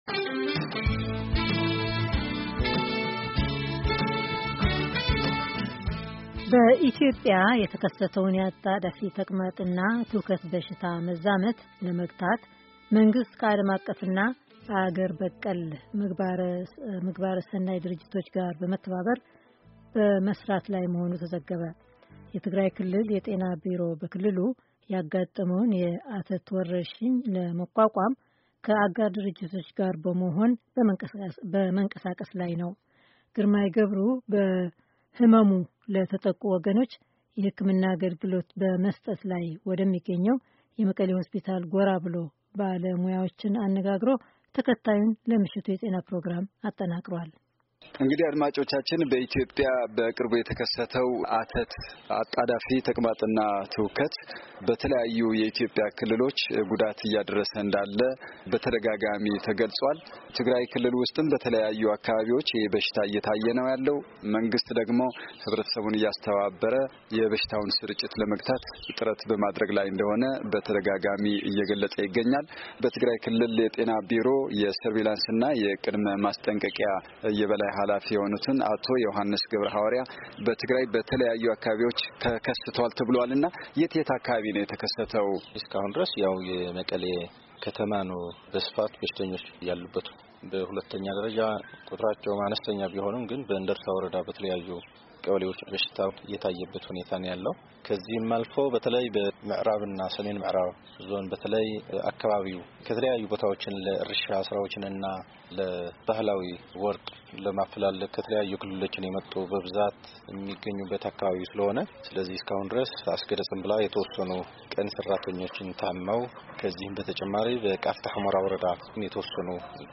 ይህ ህክምና እየተሰጠ ባለበት የመቐለ ሆስፒታል በመገኘት የህክምና ሂደቱን በመከታል እና ባለሞያዎችን በማነጋገር የተጠናከረው ዘገባ ከተያያዘው የድምፅ ፋይል ያድምጡ።